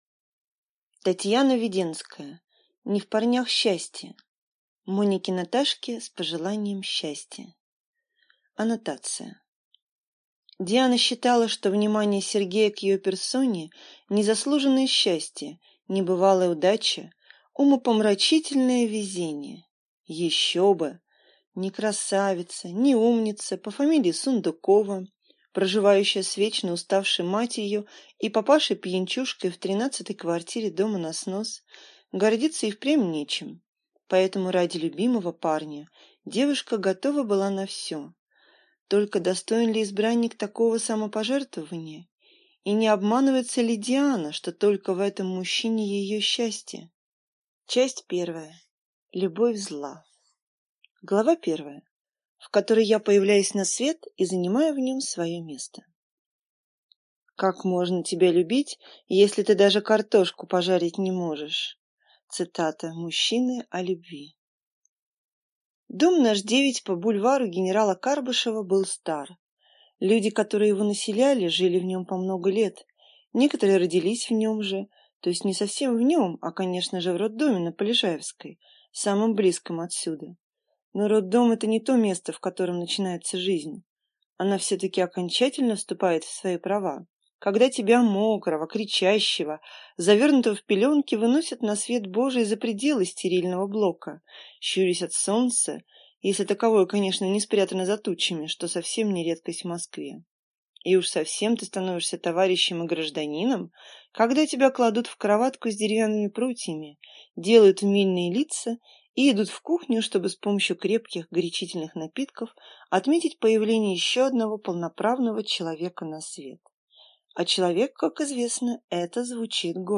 Аудиокнига Не в парнях счастье | Библиотека аудиокниг